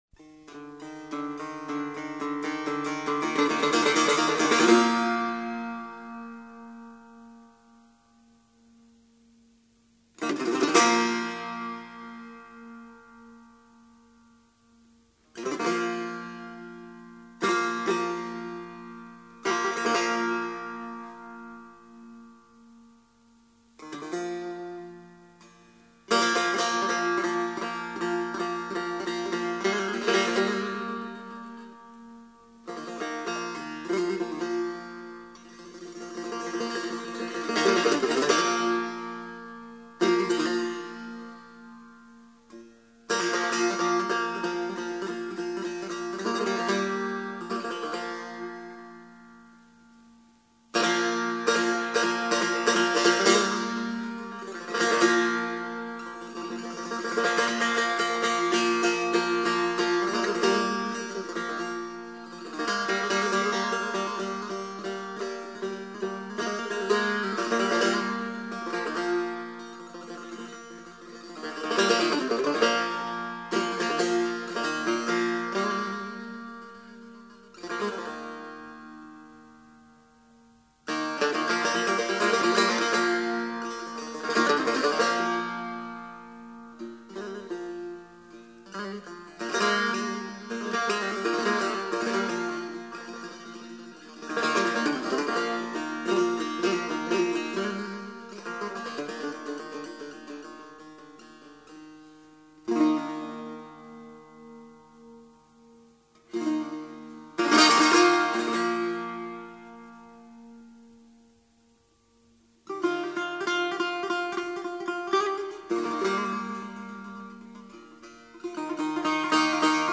تک نوازی سه تار